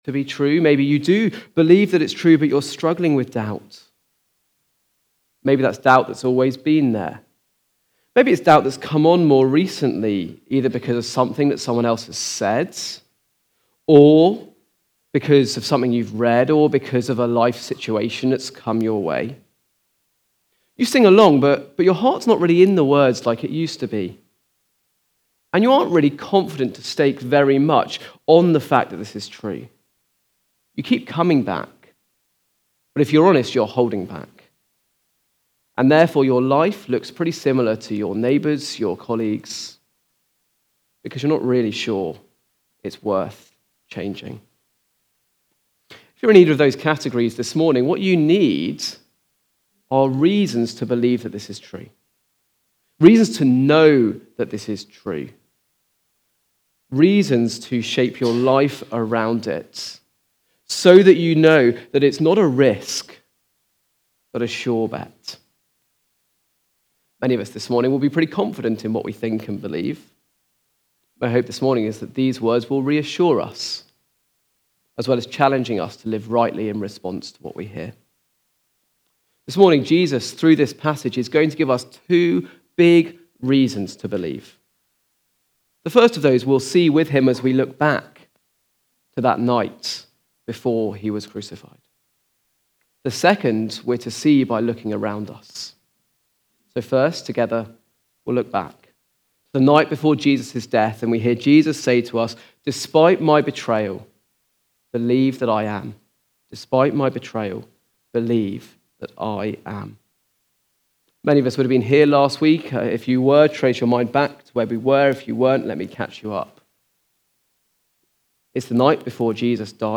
Preaching
The Betrayal (John 13:18-38) from the series Comfort and Joy. Recorded at Woodstock Road Baptist Church on 26 January 2025.